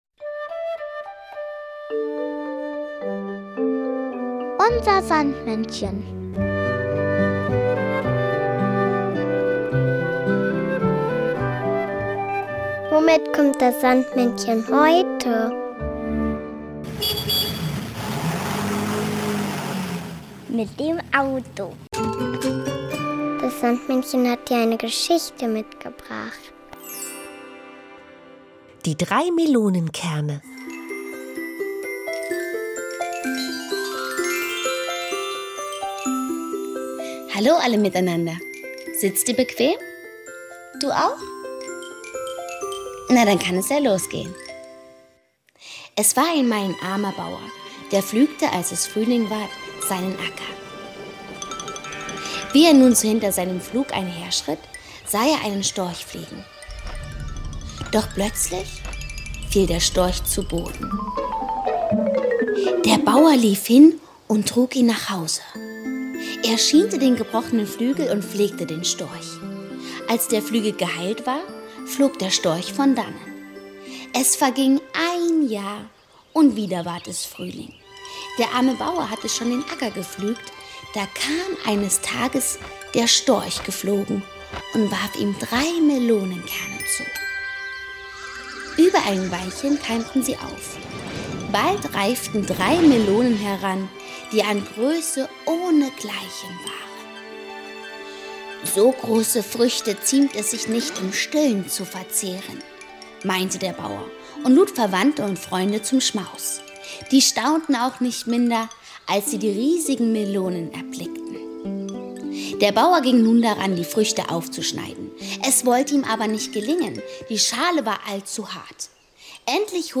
Märchen: Die drei Melonenkerne